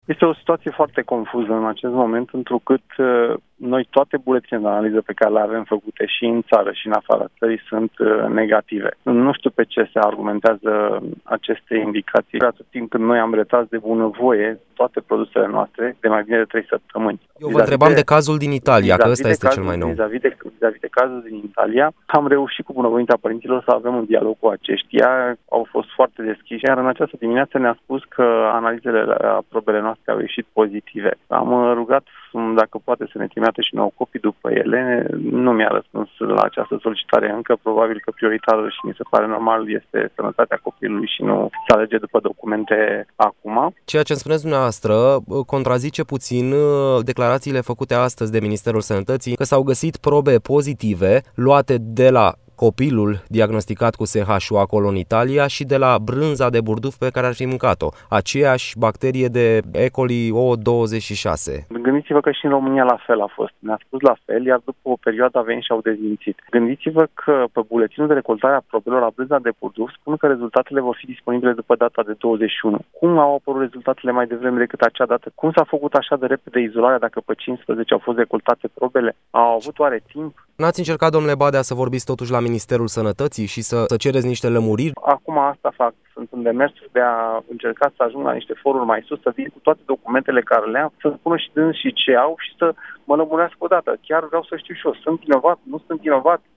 într-un interviu acordat Europa FM acuzațiilor extinse care i se aduc.